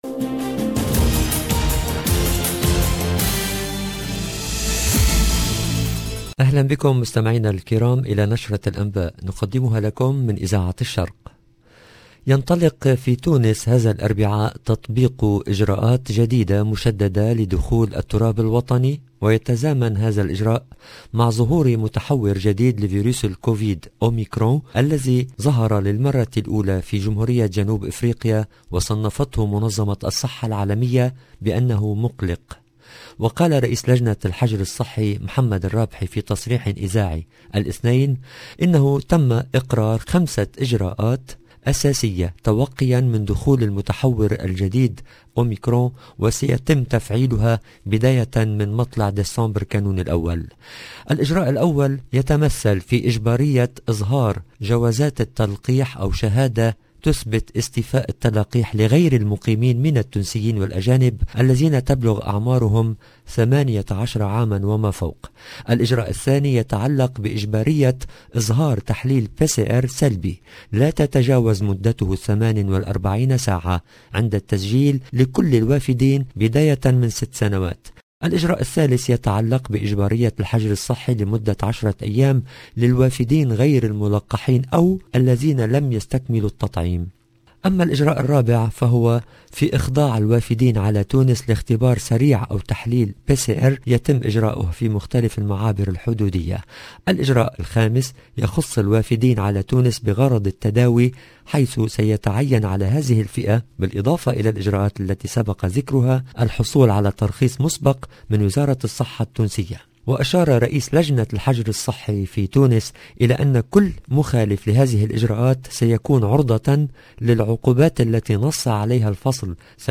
LE JOURNAL EN LANGUE ARABE DU SOIR DU 30/11/21